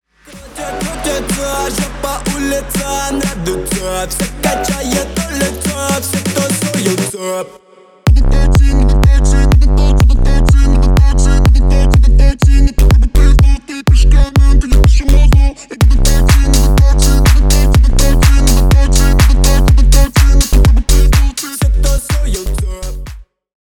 Поп Музыка
клубные